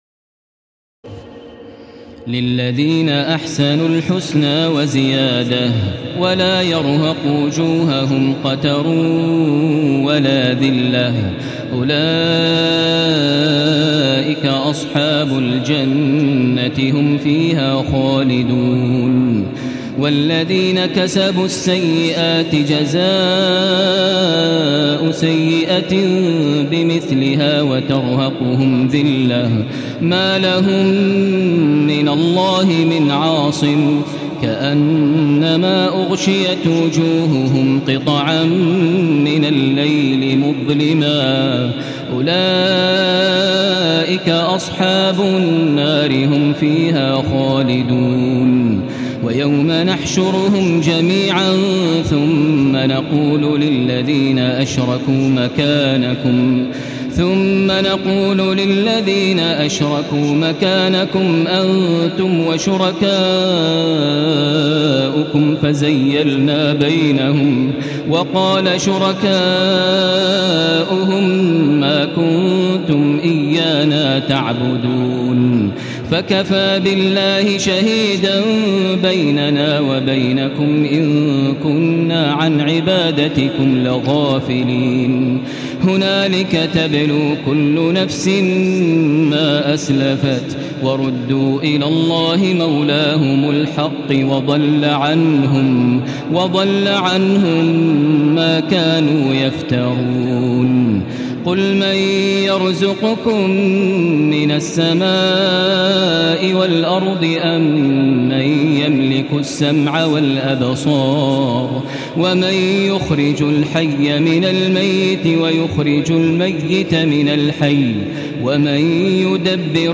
تراويح الليلة الحادية عشر رمضان 1435هـ من سورة يونس (26-109) Taraweeh 11 st night Ramadan 1435H from Surah Yunus > تراويح الحرم المكي عام 1435 🕋 > التراويح - تلاوات الحرمين